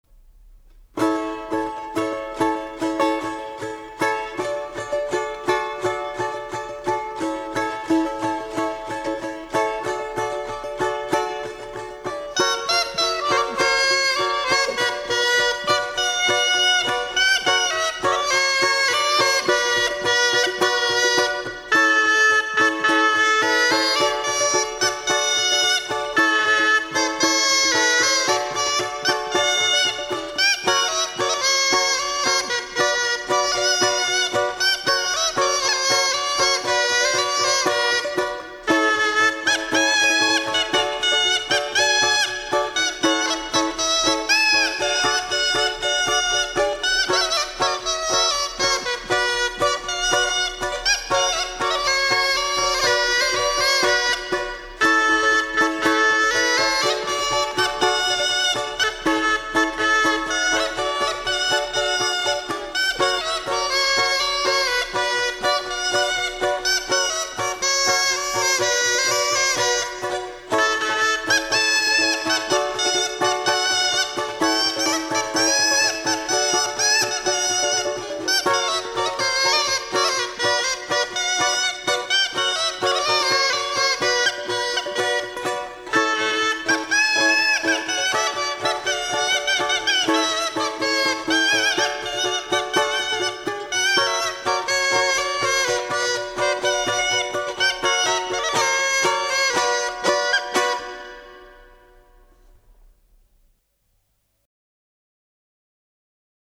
Голоса уходящего века (Курское село Илёк) Выйду я на реченьку (балалайки, рожок, инструментальная версия)